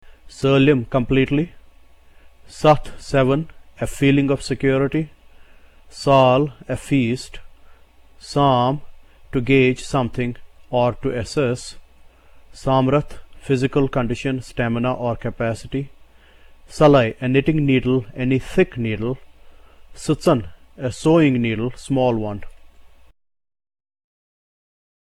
The symbol S is pronounced as one would pronounce S in the English word SIT.